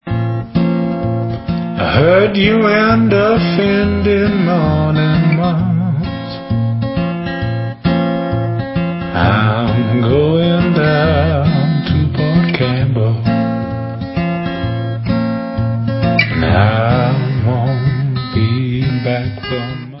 sledovat novinky v oddělení Blues